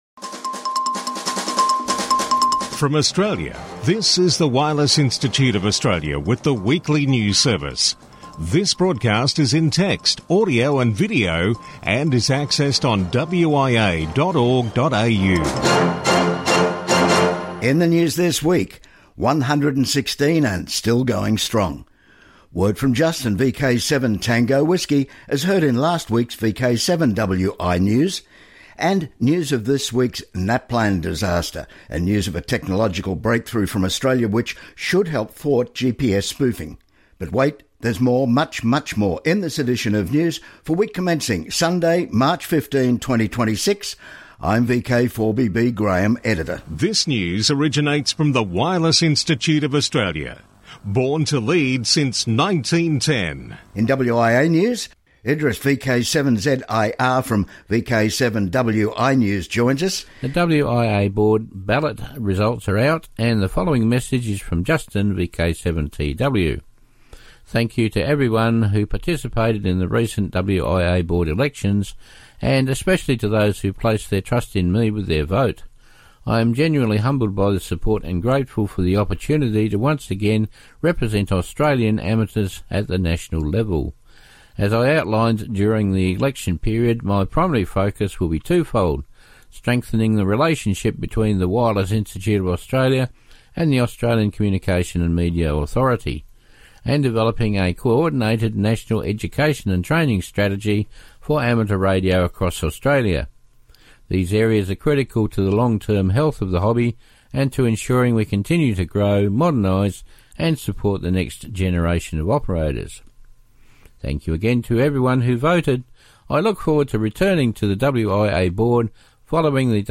2026 MARCH 15 WIA NATIONAL NEWS BROADCAST ON VK1WIA